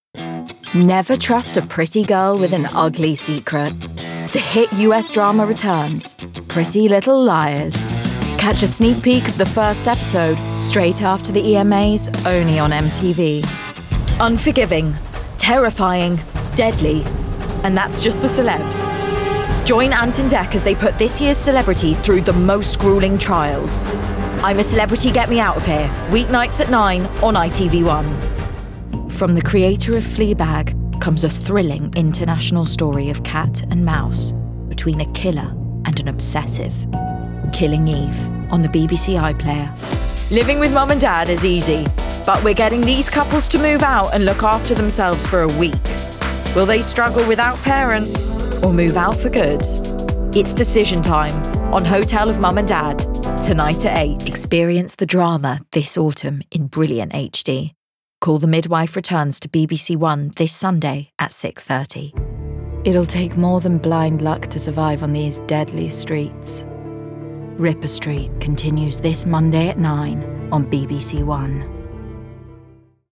Promo Reel
RP ('Received Pronunciation')
Promo, Cool, Smooth, Confident